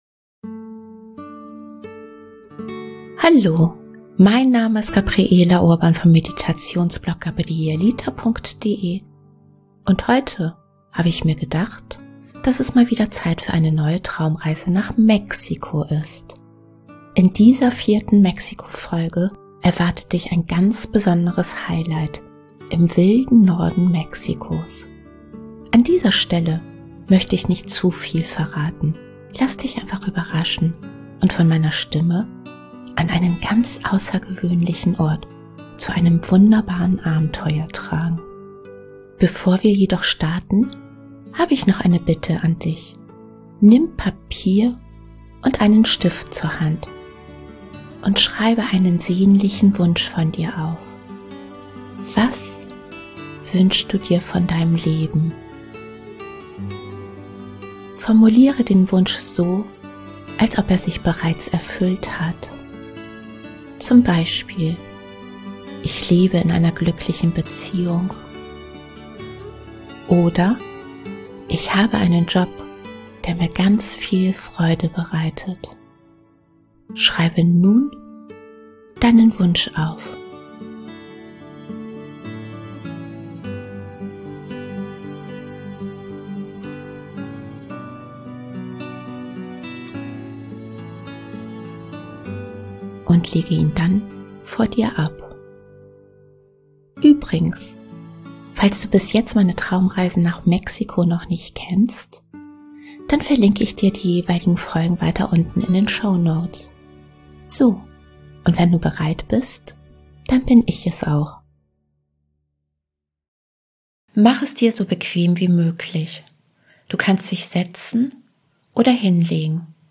In dieser 4. Mexiko-Folge erwartet dich ein ganz besonderes Highlight in der wilden Wüste Zentralmexikos. An dieser Stelle möchte ich nicht zu viel verraten, lass dich in dieser geführten Traumreise einfach überraschen – und von meiner Stimme an einen ganz außergewöhnlichen Ort in Mexiko tragen.